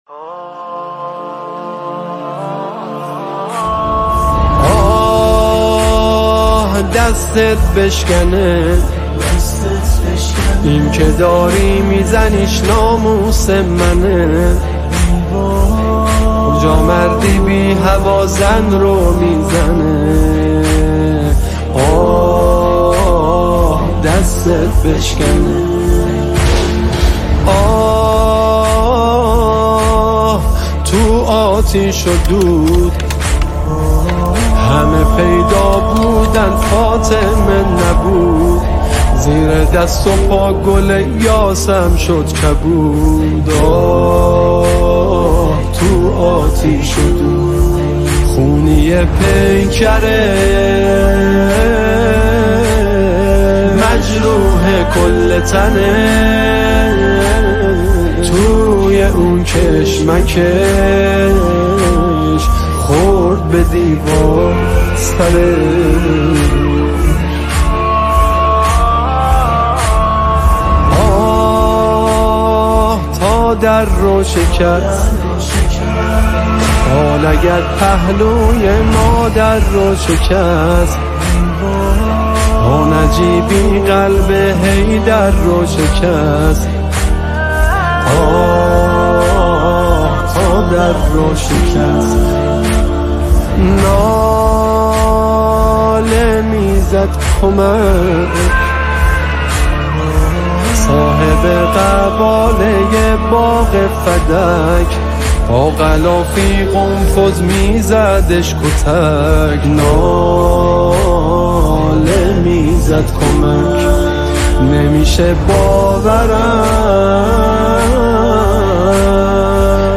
با نوای زیبا و دلنشین